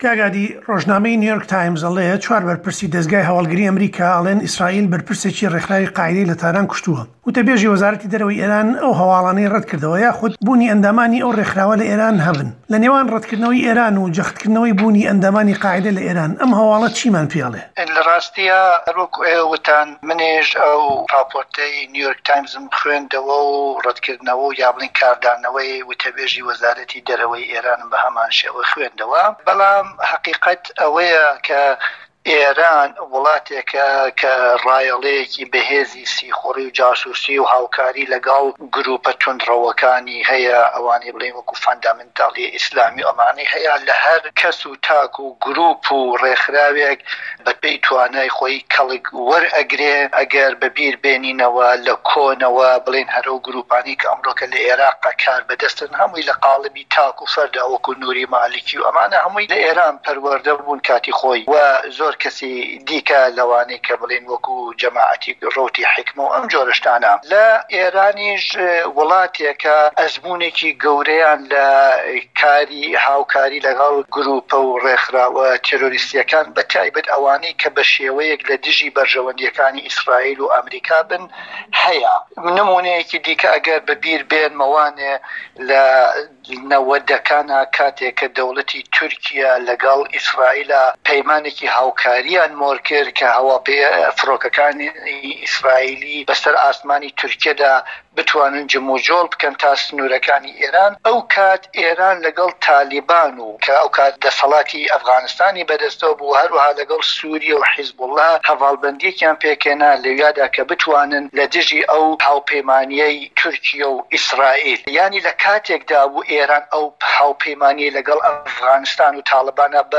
ئێران - گفتوگۆکان